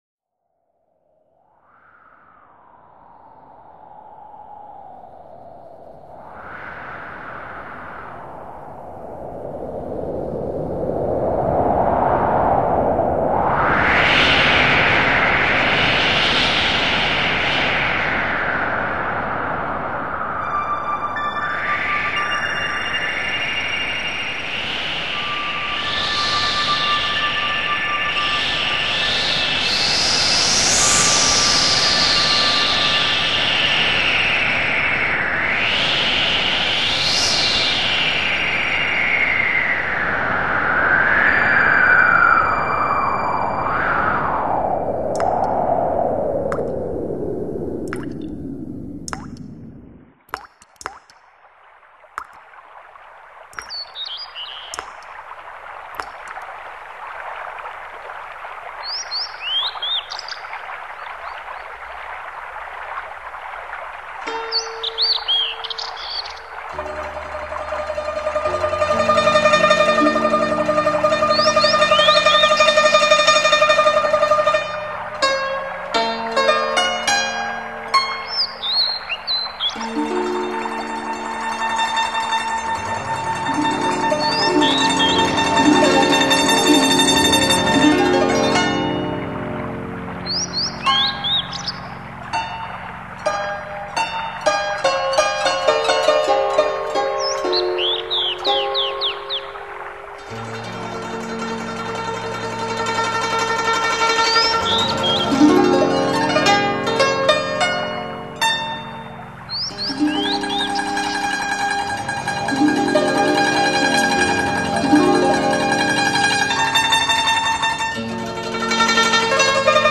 以音乐语言描绘山间流水
让承载宇宙和谐频率的自然音效与源自古老的简朴琴韵引领你进入平和、舒坦的绿色空间，